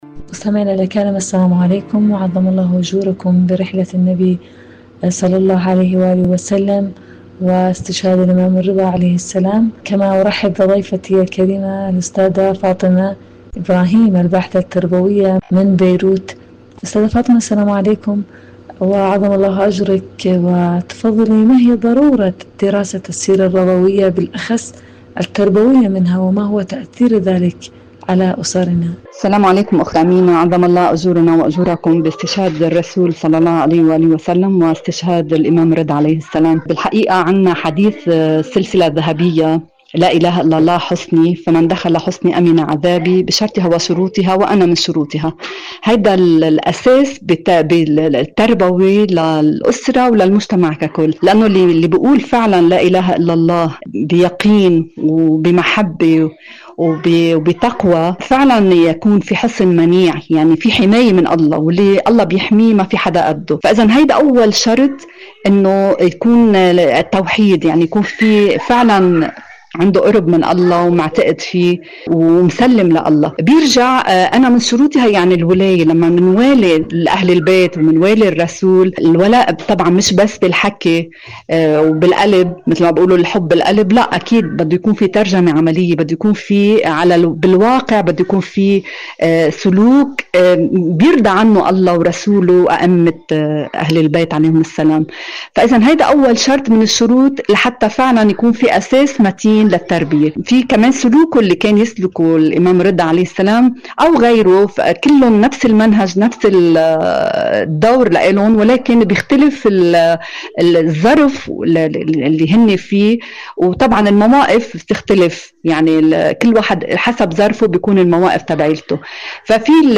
إذاعة طهران- معكم على الهواء: مقابلة إذاعية